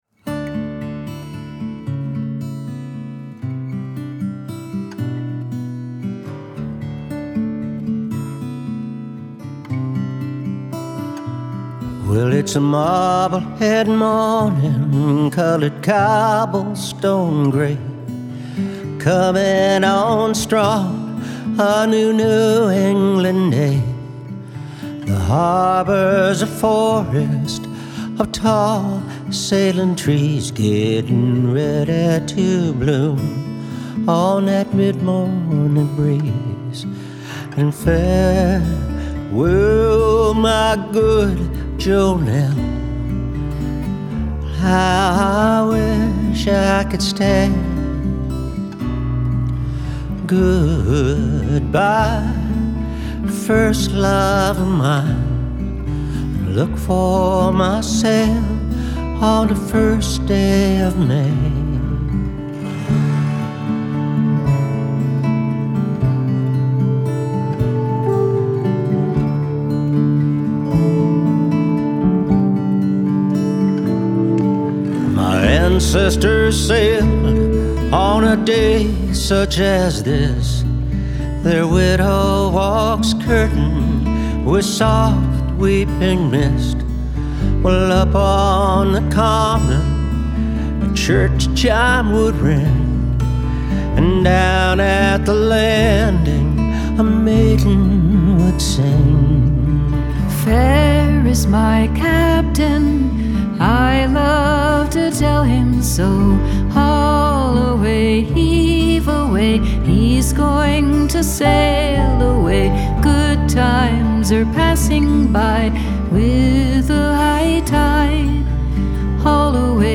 and their half century as a folk duo.